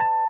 GUnit Keyz.wav